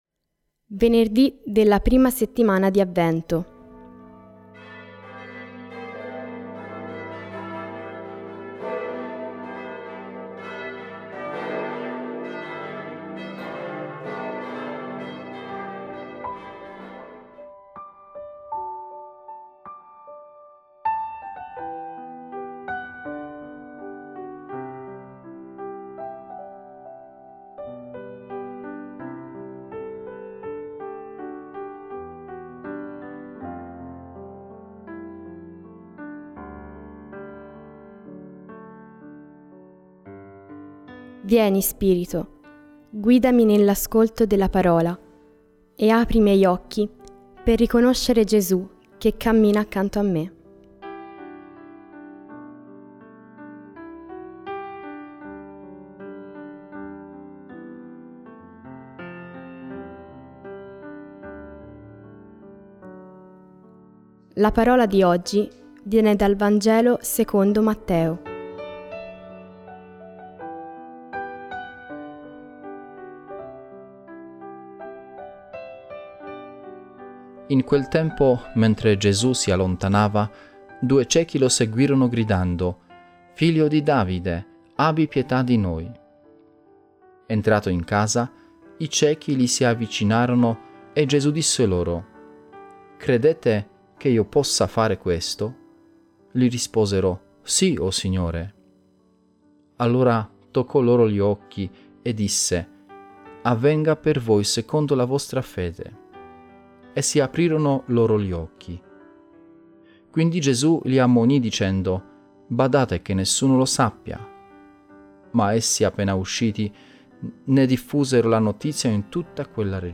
Voci narranti